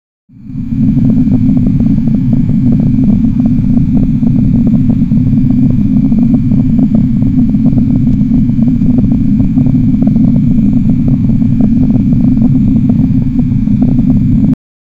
spaceship engine
spaceship-engine-cuj6nq4i.wav